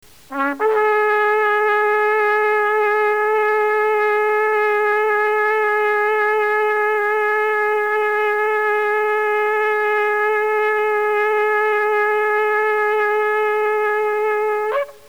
The Tekieh Gadol  (Gadol means great, big or high) is a very long held and loud Tekieh.
tekieh gadol.mp3